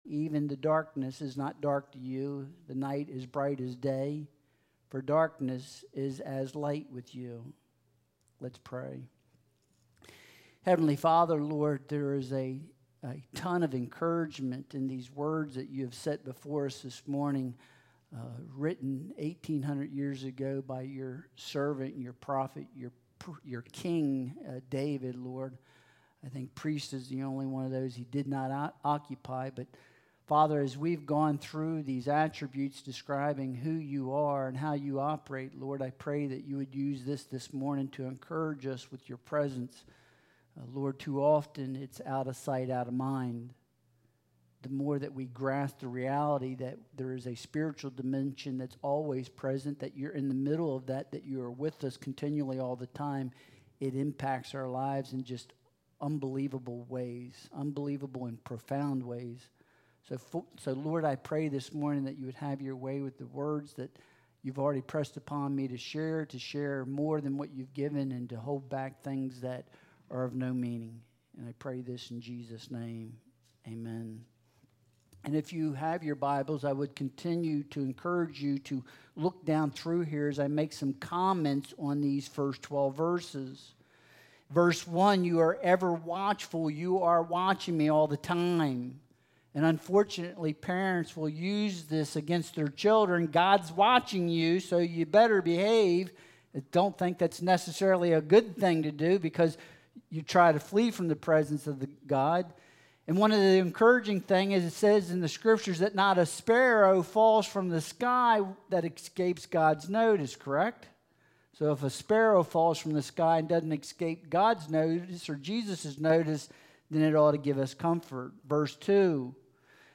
Psalm 139.1-12 Service Type: Sunday Worship Service The Attributes of God